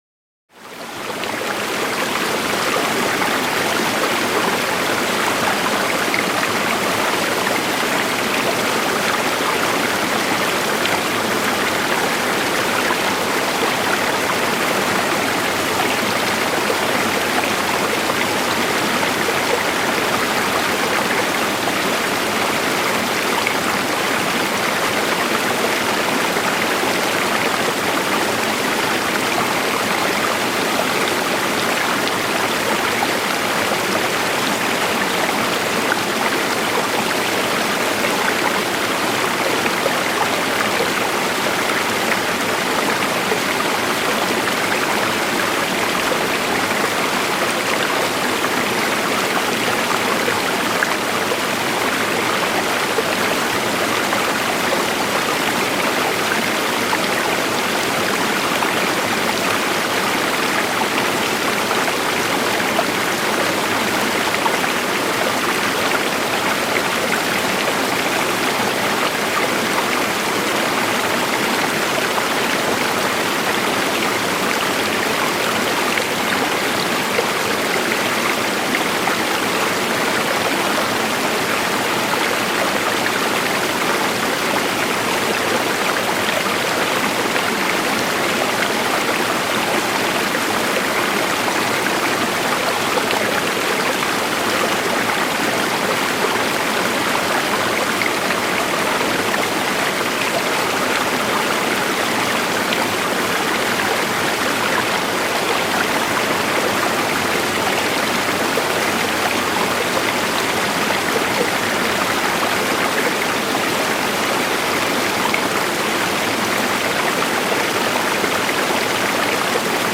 GRÜNE ENTSPANNUNGS-LANDSCHAFT: Wald-Kaskade mit rauschenden Wasser